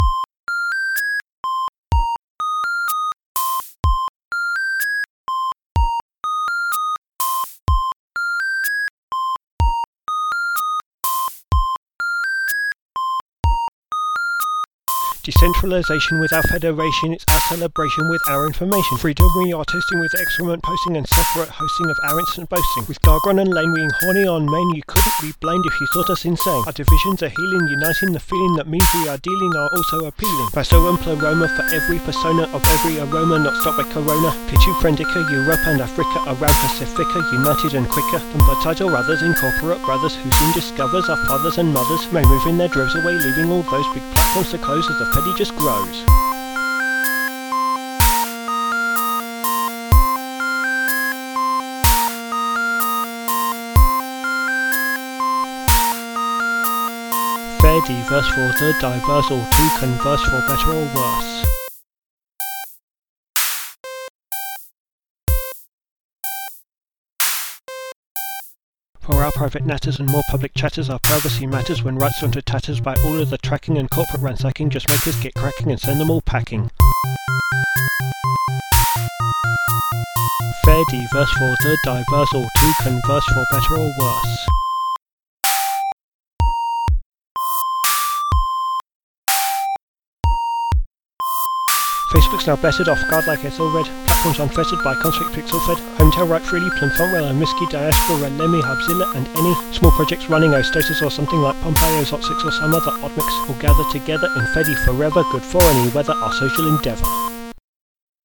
simple hiphop track over a chiptune